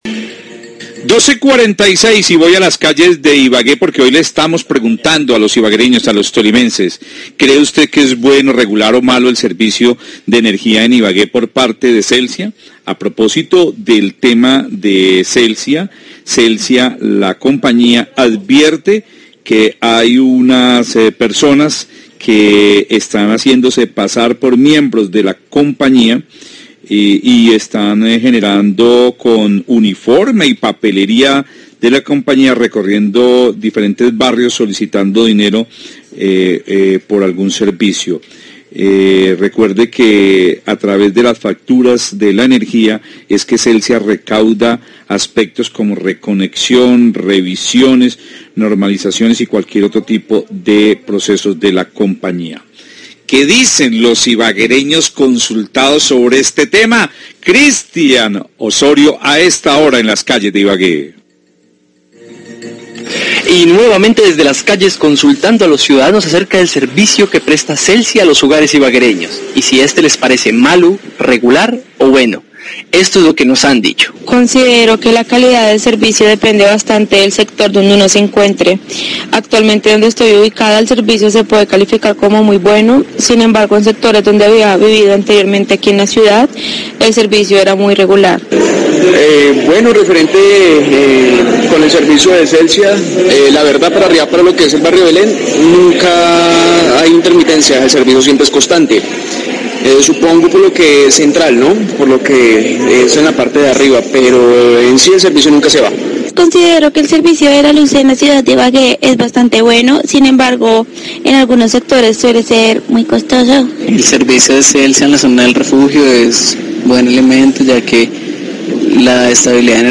Radio
Tras el anuncio de Celsia alertando a la ciudadanía para que eviten dar dinero a los ciudadanos, ya que la empresa solo recauda a través de la factura, La FM Ibagué hizo una encuesta entre los transeúntes sobre el servicio de Celsia en Ibagué y todos los entrevistados afirmaron estár muy contentos o conformes con el servicio.